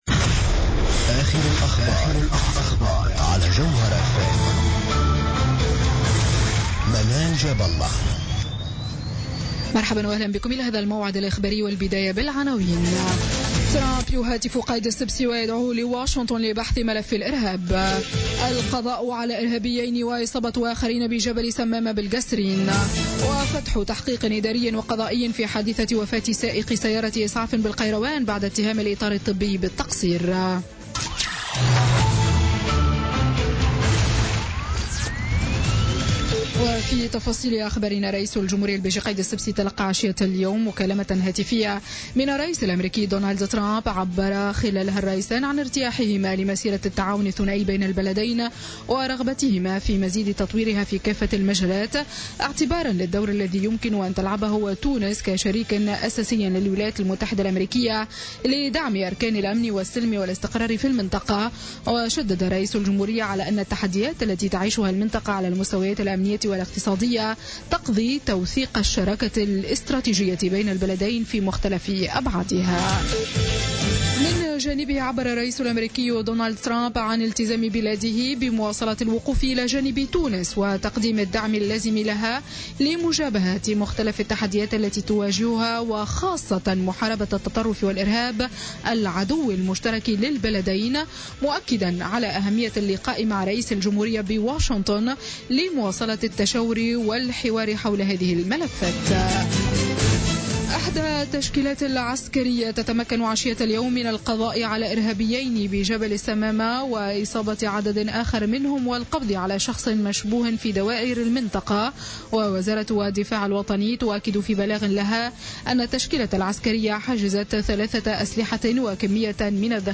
نشرة أخبار السابعة مساء ليوم الجمعة 17 فيفري 2017